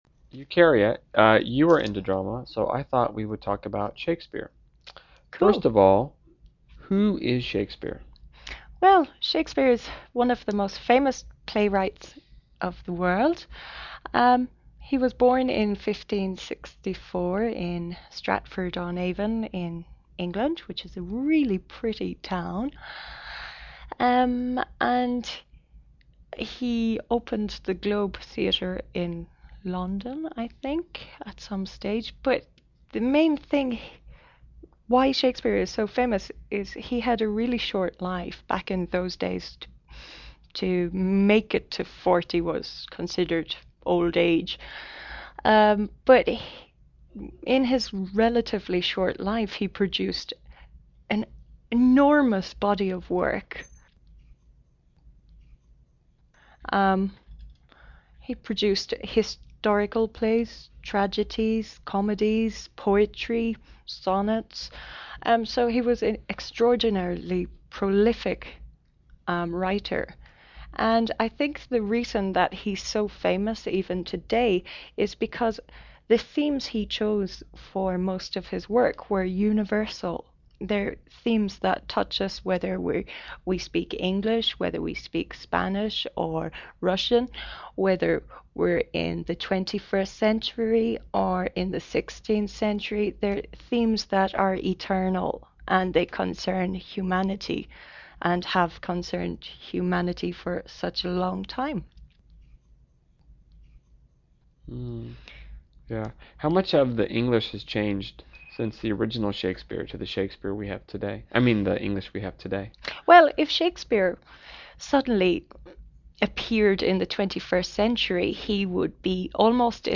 英语访谈对话 528 Shakespeare 听力文件下载—在线英语听力室